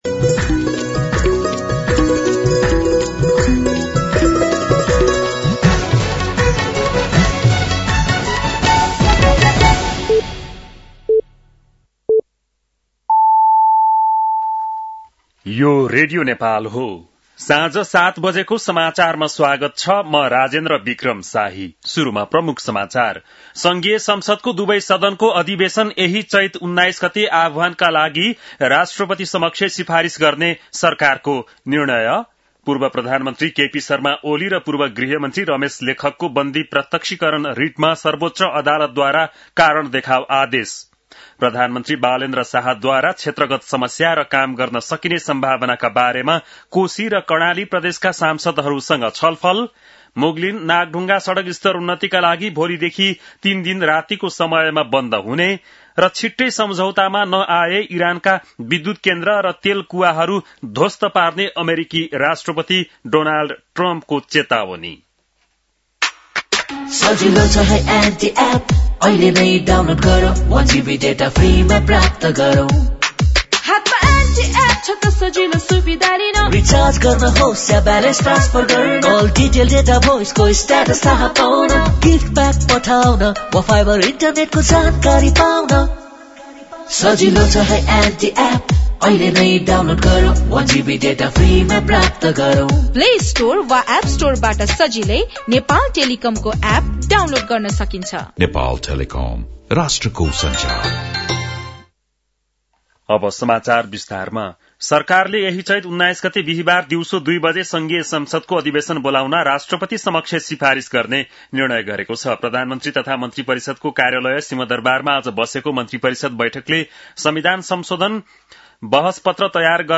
बेलुकी ७ बजेको नेपाली समाचार : १६ चैत , २०८२
7-pm-news-12-16.mp3